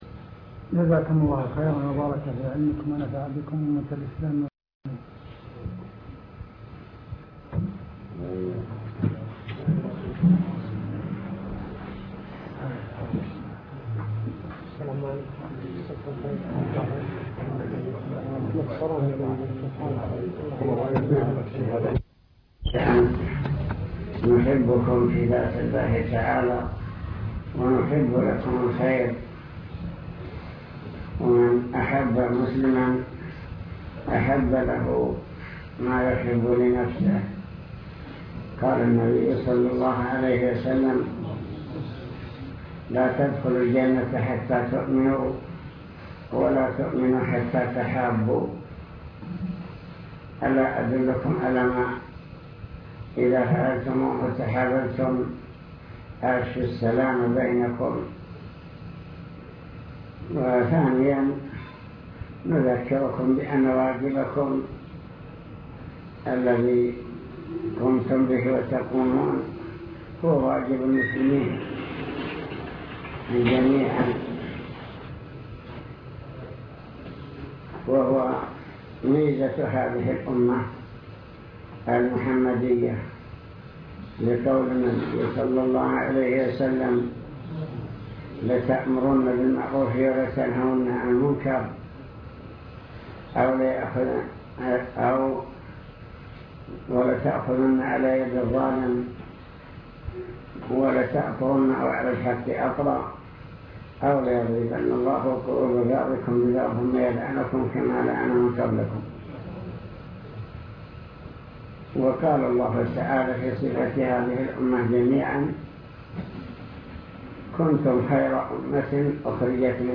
المكتبة الصوتية  تسجيلات - لقاءات  كلمة الهيئة